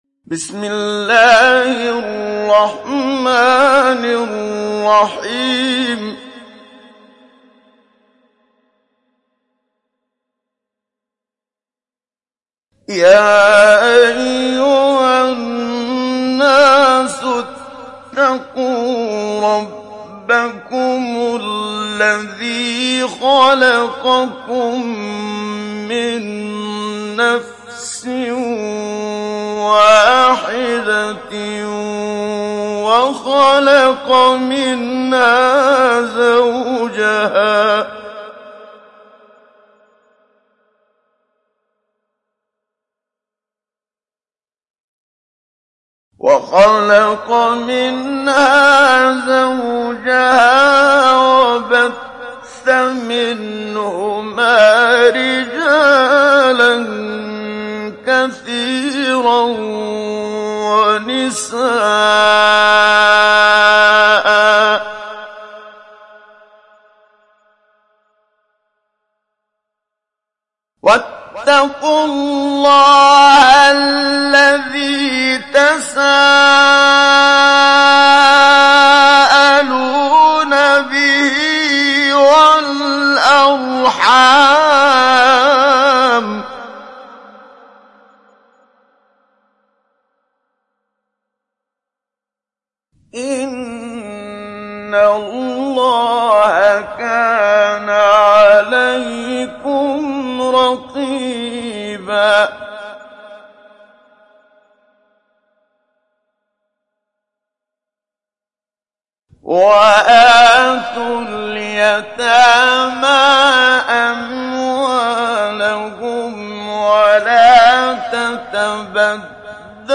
Surat Annisa Download mp3 Muhammad Siddiq Minshawi Mujawwad Riwayat Hafs dari Asim, Download Quran dan mendengarkan mp3 tautan langsung penuh
Download Surat Annisa Muhammad Siddiq Minshawi Mujawwad